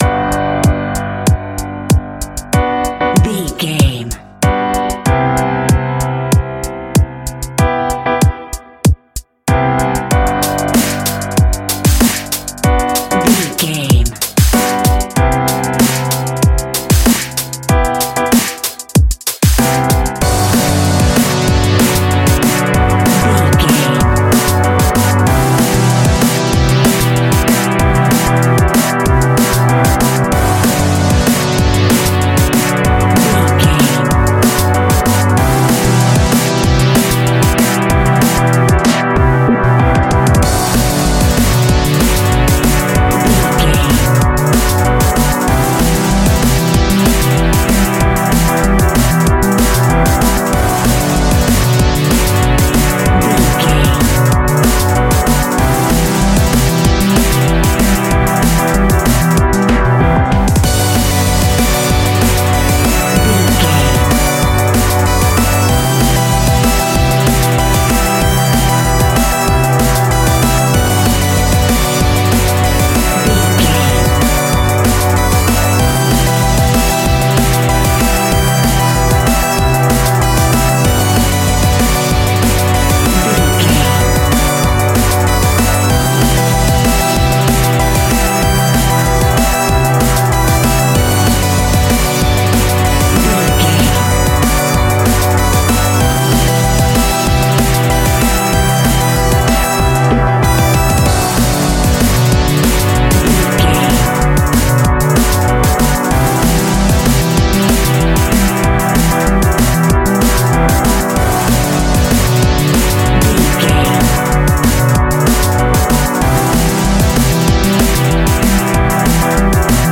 Drum and Bass Digital.
Aeolian/Minor
Fast
aggressive
dark
groovy
futuristic
frantic
drum machine
electric piano
synthesiser
sub bass
synth leads